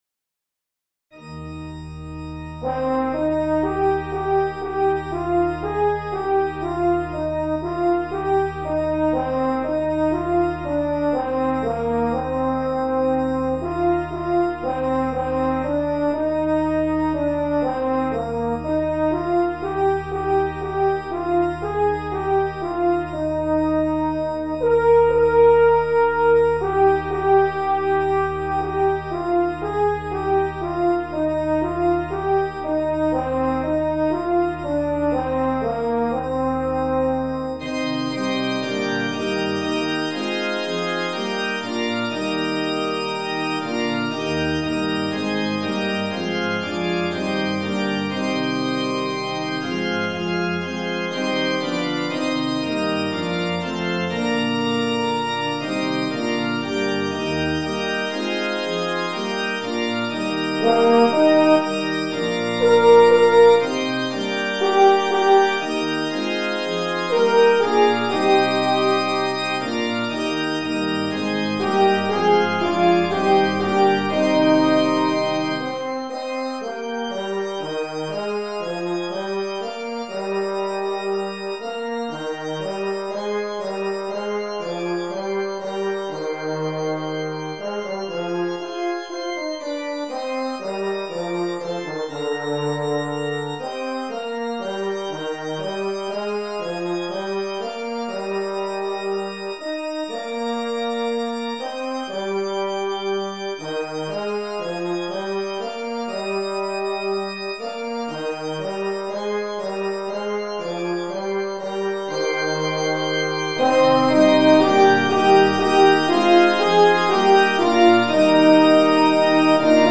Voicing: Horn Solo